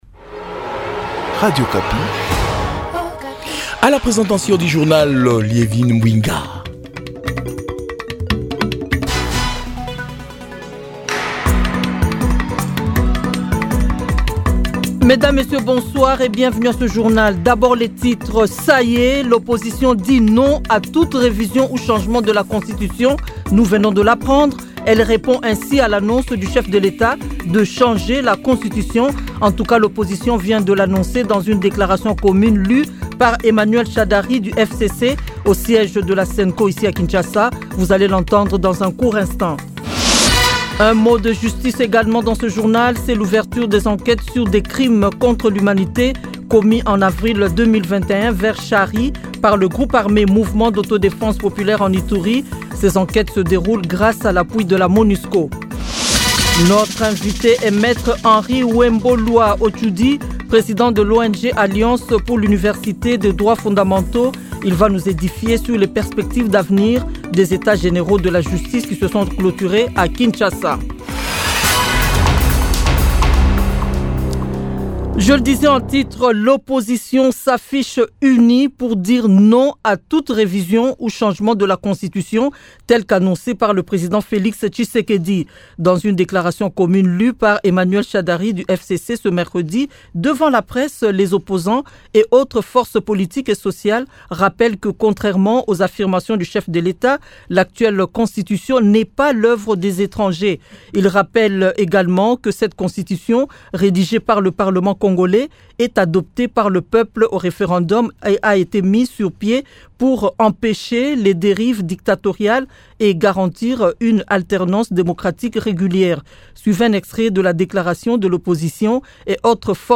Journal Francais Soir